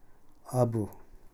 スピーカあぶ〈穴〉（多良間方言）
bu ビー・ユー